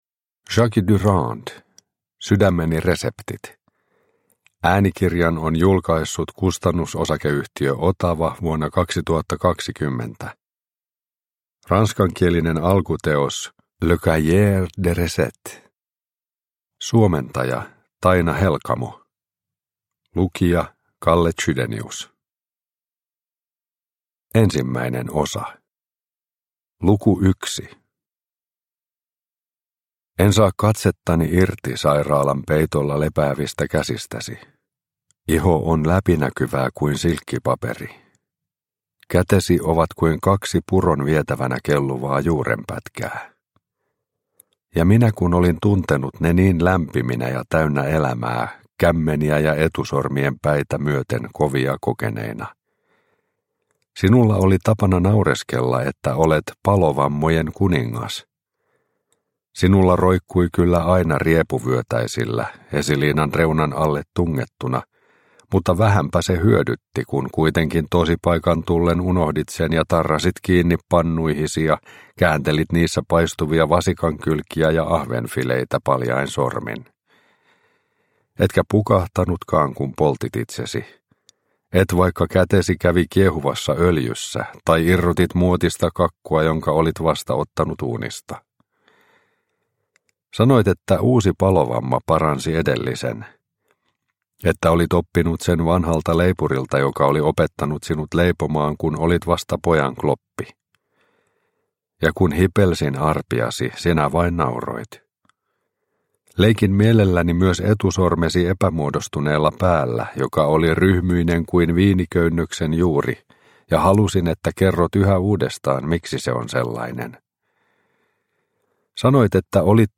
Sydämeni reseptit – Ljudbok – Laddas ner